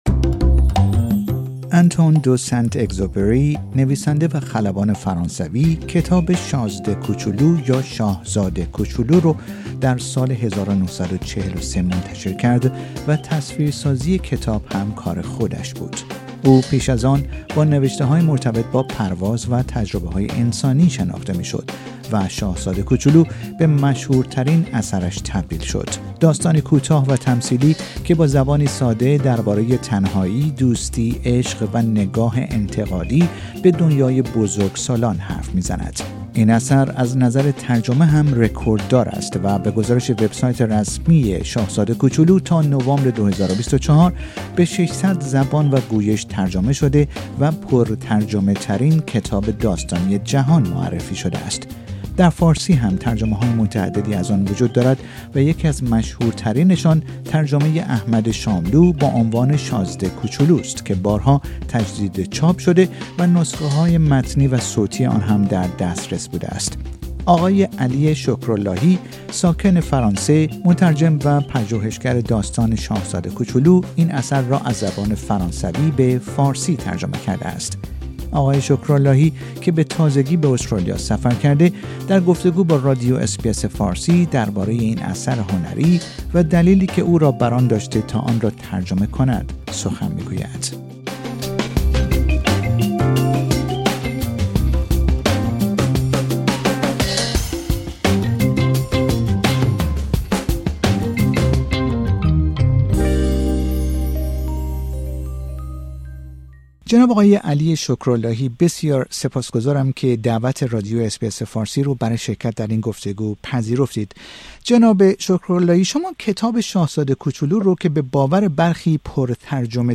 در گفتگو با رادیو اس بی اس فارسی درباره این اثر هنری، و دلیلی که او را بر آن داشته تا آن را ترجمه کند، سخن می گوید.